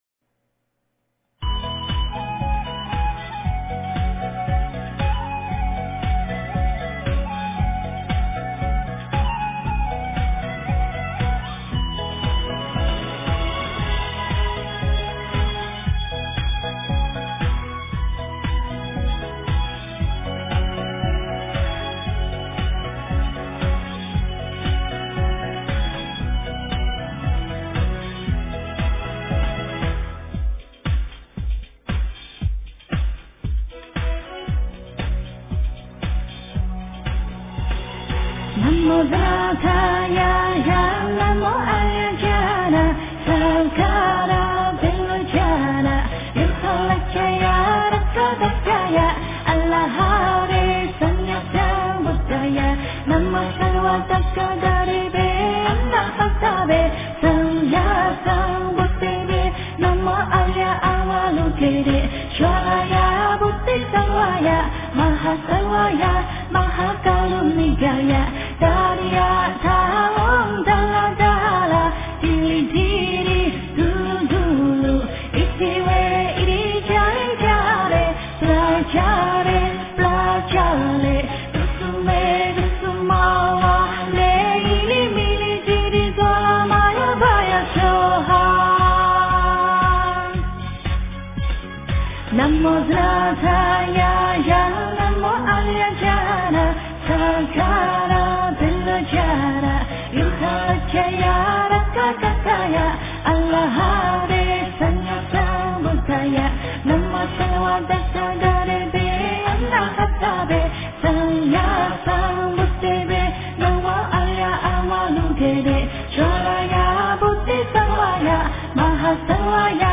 诵经
佛音 诵经 佛教音乐 返回列表 上一篇： 心经 下一篇： 心经 相关文章 六字真言颂-蒙语--群星 六字真言颂-蒙语--群星...